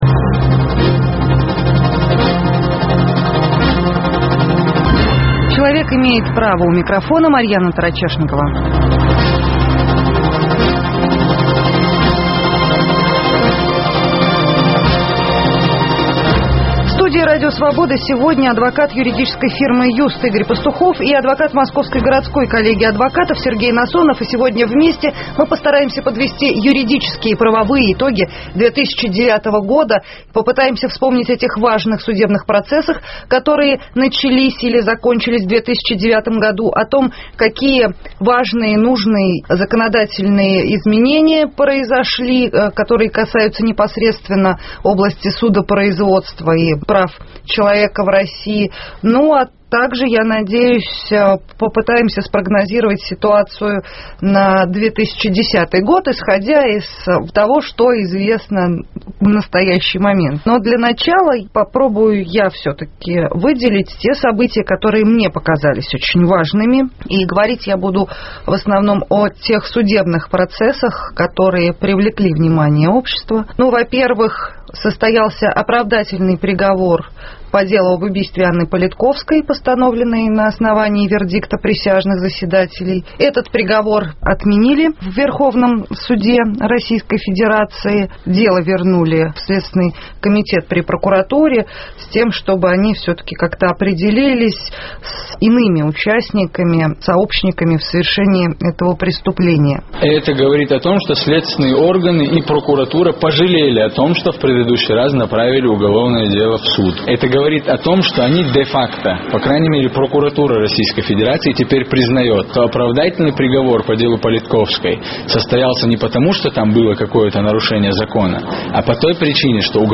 Юридические итоги 2009 года в студии РС подводят члены Независимого экспертно-правового совета